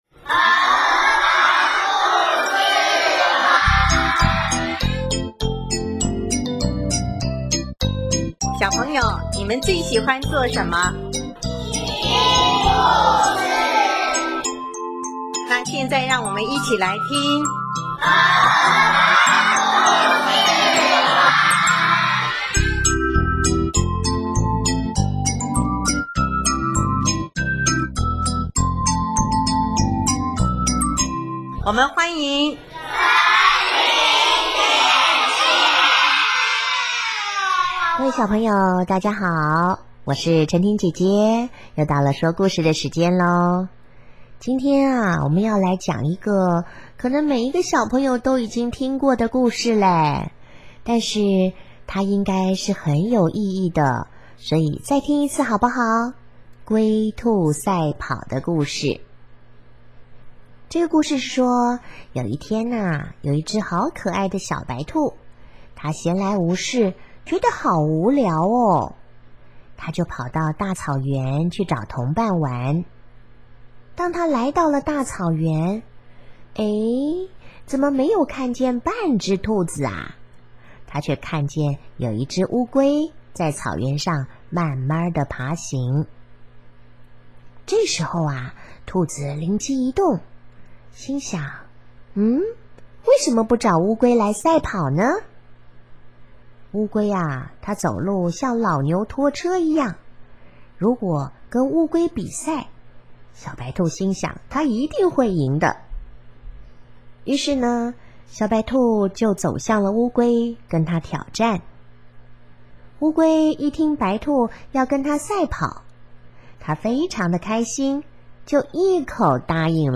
【儿童故事】|龟兔赛跑